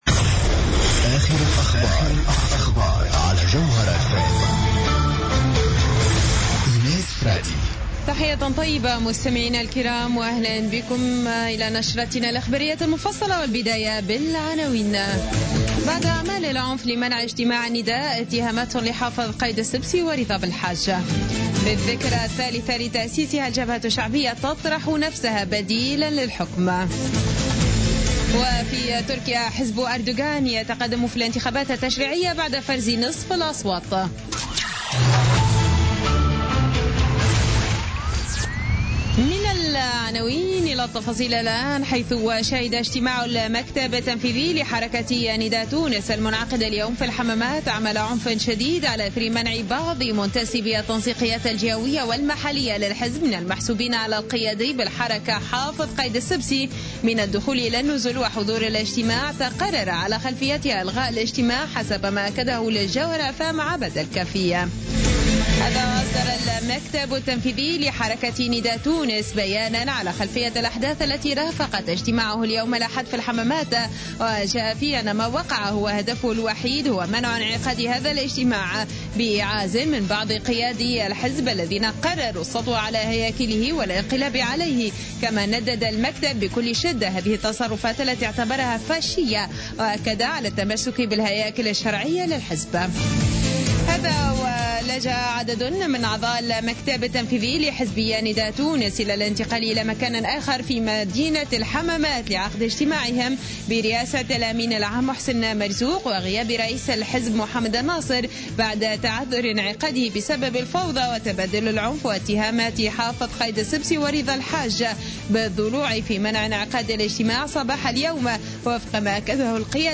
نشرة أخبار السابعة مساء ليوم الأحد غرة نوفمبر 2015